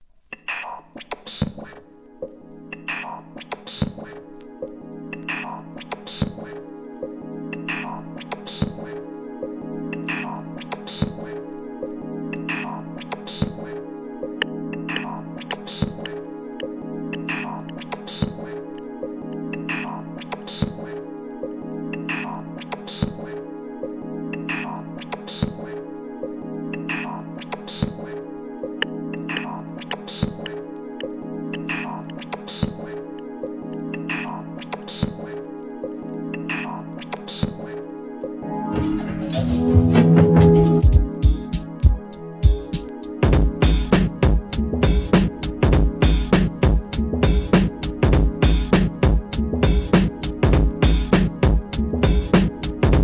Electronic, ブレイクビーツ